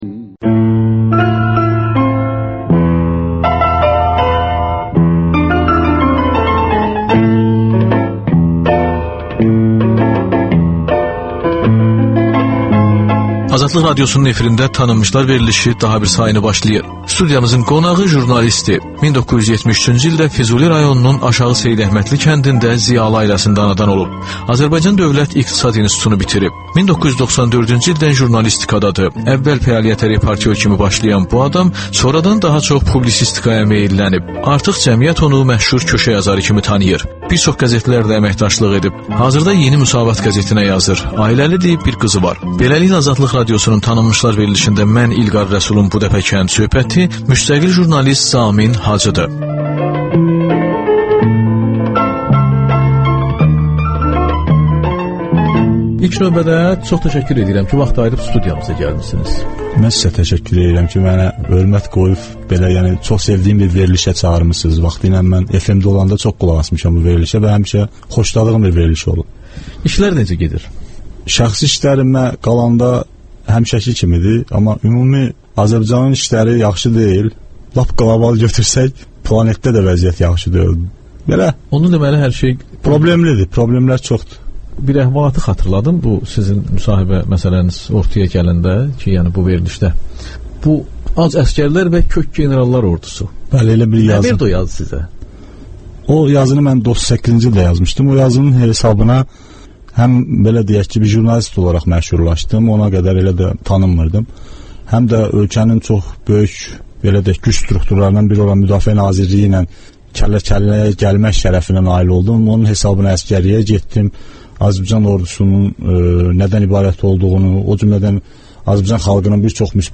Ölkənin tanınmış simaları ilə söhbət Təkrar